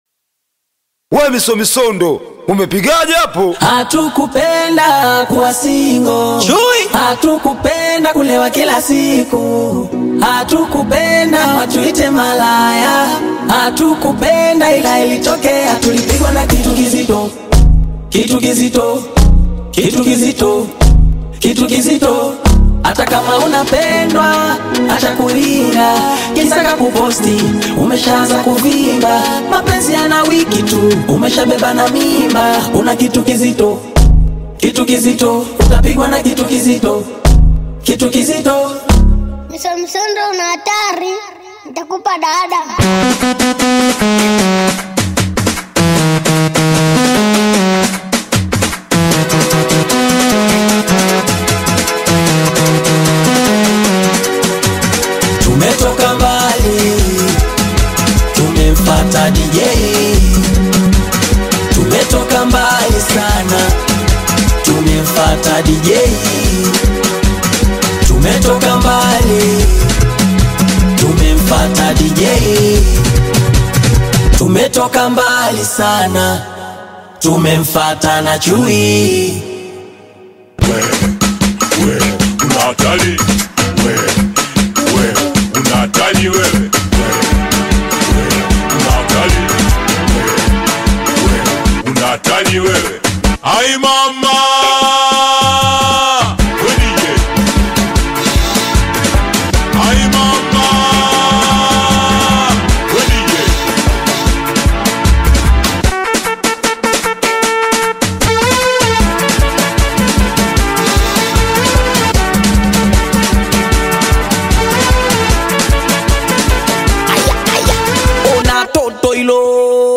a lively and energetic vibe